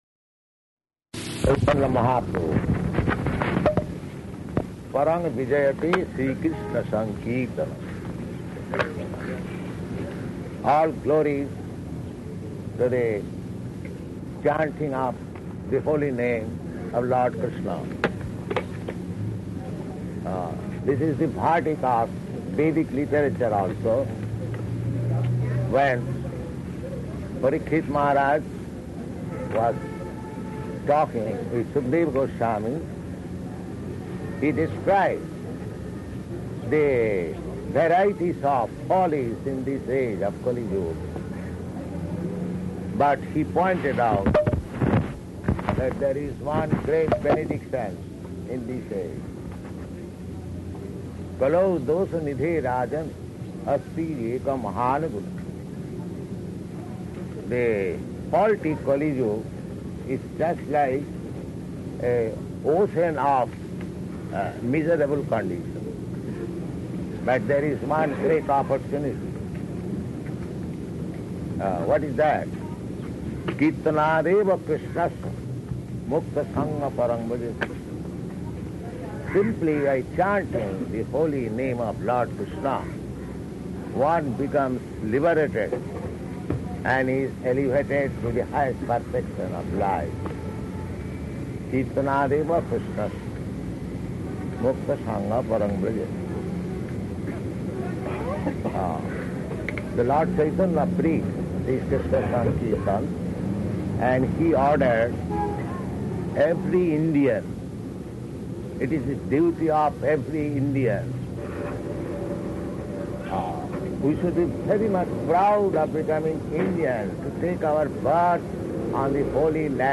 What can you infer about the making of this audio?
Location: Delhi